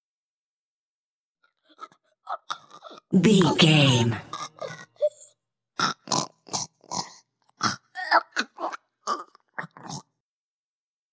Female choke
Sound Effects
scary
ominous
disturbing